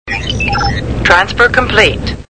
Star Trek TNG Sound Effects -Computer new mail.mp3